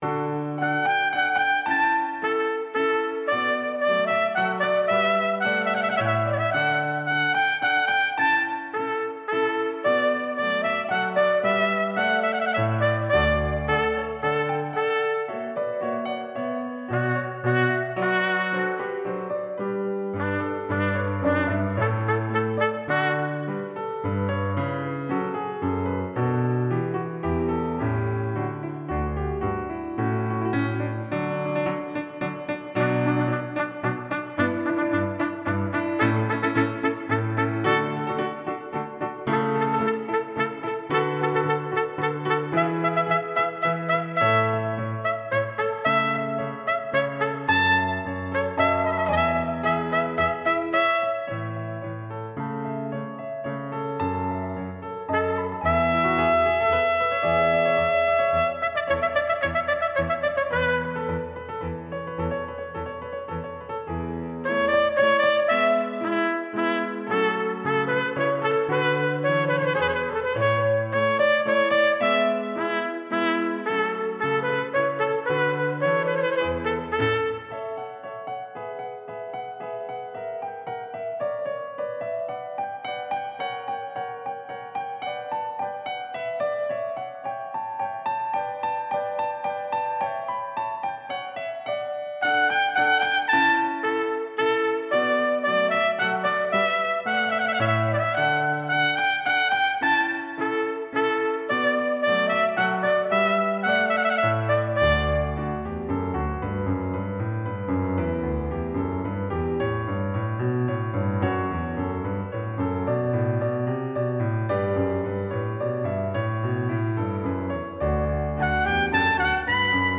trumpeter